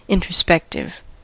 introspective: Wikimedia Commons US English Pronunciations
En-us-introspective.WAV